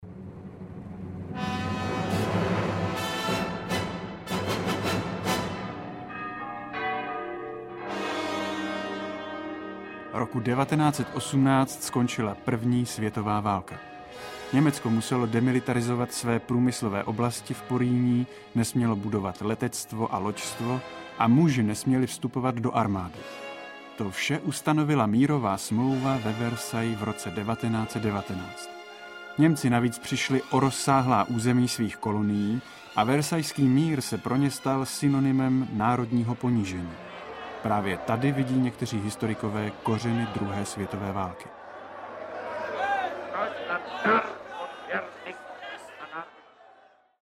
Přináší řadu méně známých dokumentů a ty, o nichž jste zatím jen četli, nabízí k poslechu v originálu.
Audiokniha